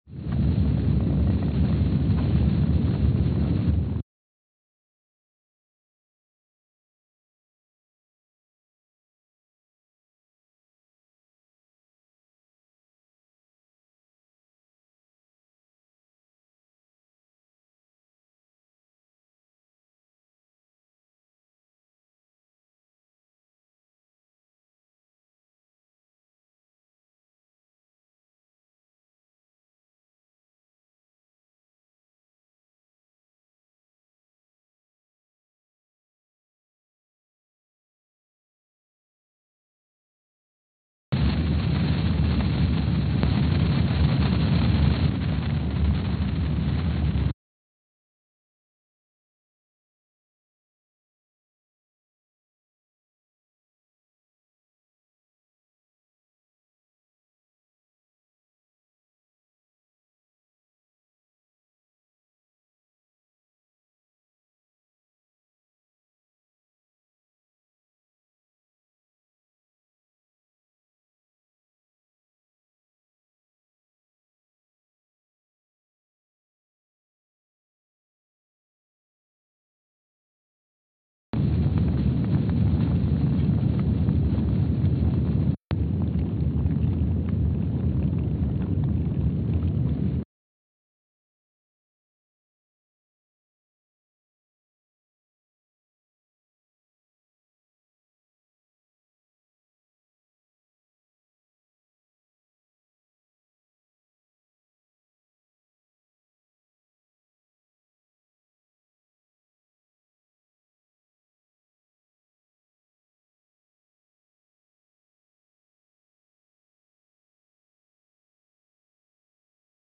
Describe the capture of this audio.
Palmer Station, Antarctica (seismic) archived on April 10, 2025 Sensor : STS-1VBB_w/E300 Speedup : ×500 (transposed up about 9 octaves) Loop duration (audio) : 05:45 (stereo) SoX post-processing : highpass -2 90 highpass -2 90